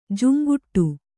♪ jumguṭṭu